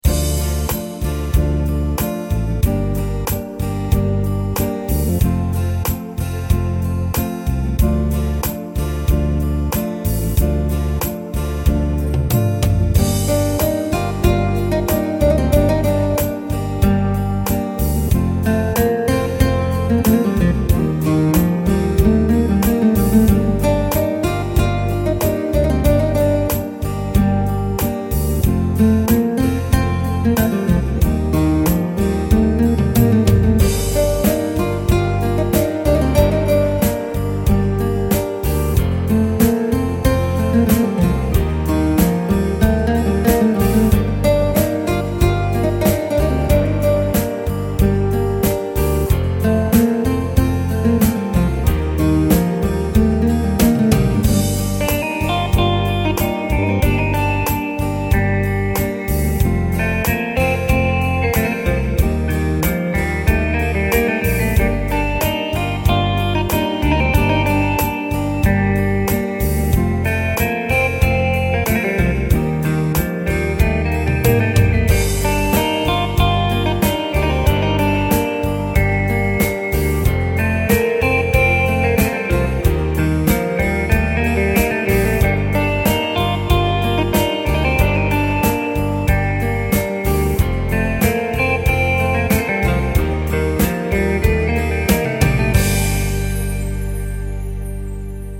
yeni yaptığım kısa bir demo aleti merak edenler için